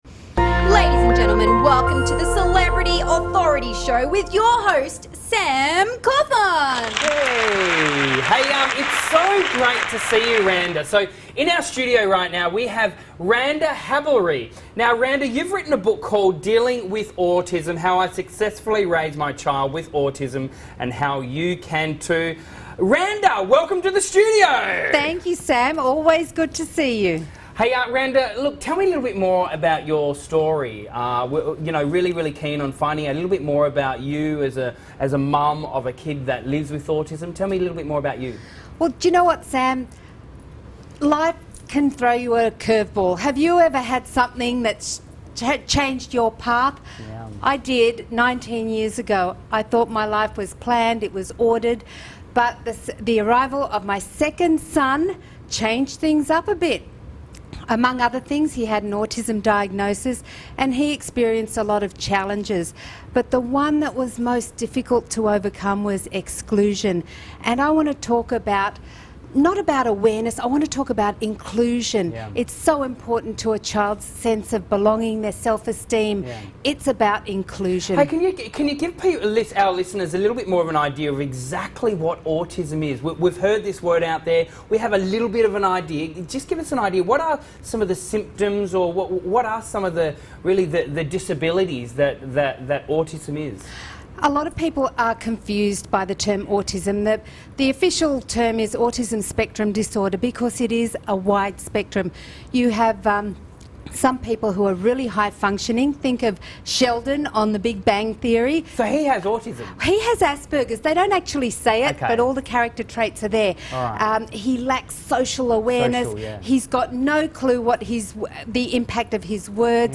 Author, speaker and social inclusion educator